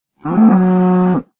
Sound / Minecraft / mob / cow2